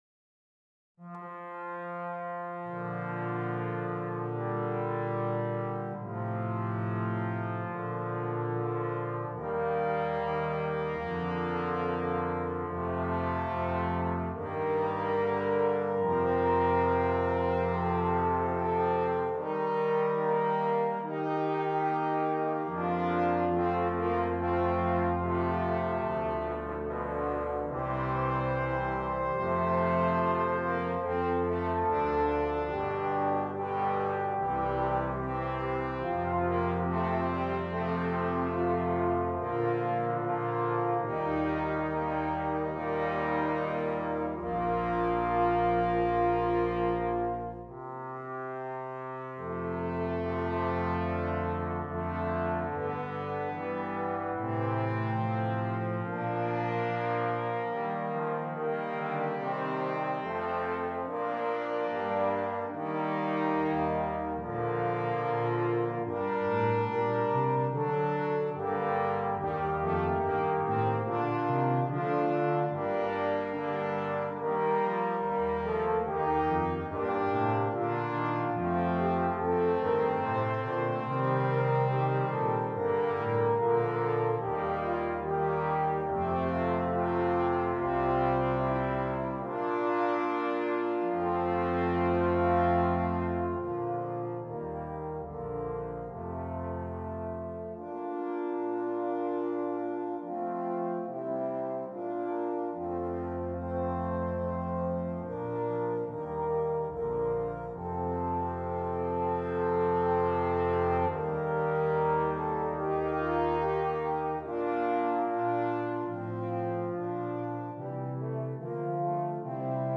Brass Band
2 Horns, 2 Trombones, Tuba